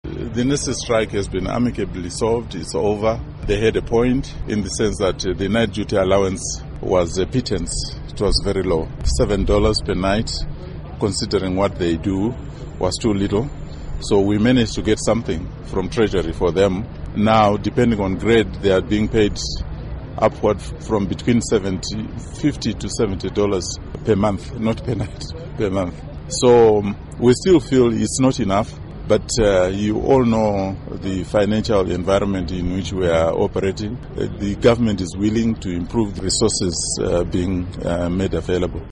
Interview With Lovemore Mbengeranwa on Nurses Allowances